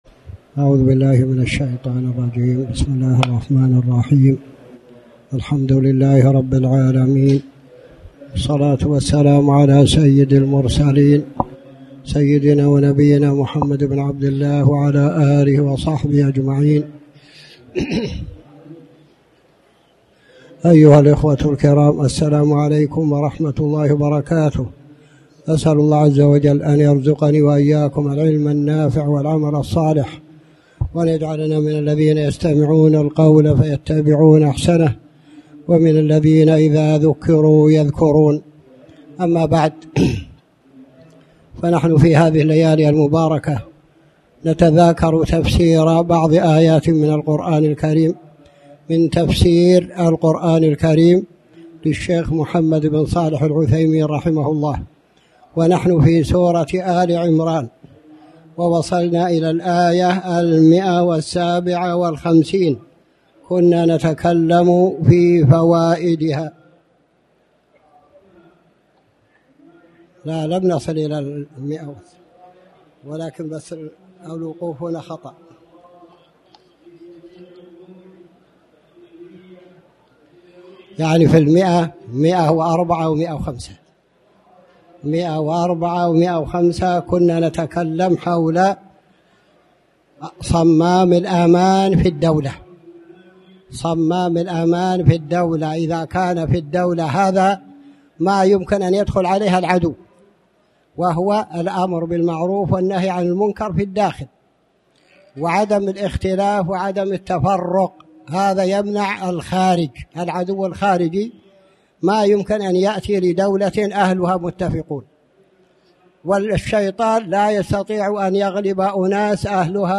تاريخ النشر ٢٢ ربيع الأول ١٤٣٩ هـ المكان: المسجد الحرام الشيخ